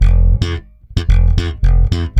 -AL DISCO F#.wav